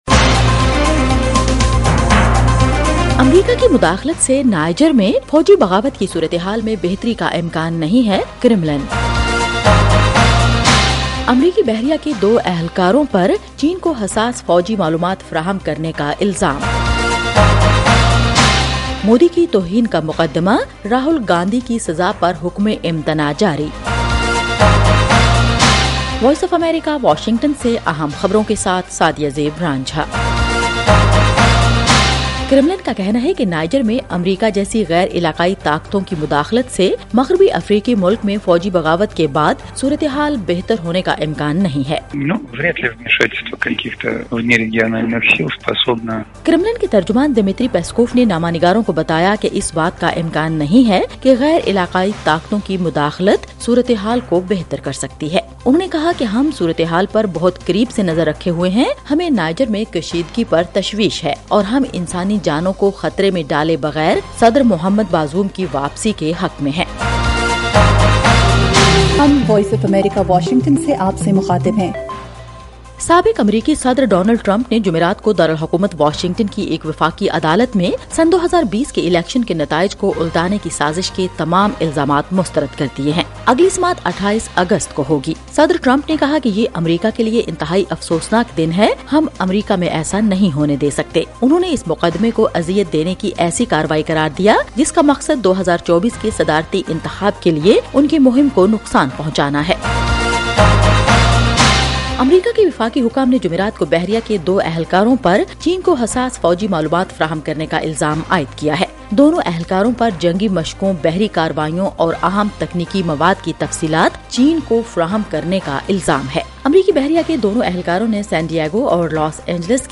ایف ایم ریڈیو نیوز بلیٹن : رات 8 بجے